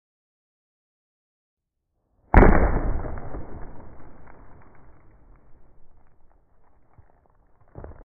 **🎬 INSANE SLOW MO! Bullet RIPS sound effects free download
**Satisfying ASMR Sounds** (*CRACK!* *SPLAT!* *SQUISH!*)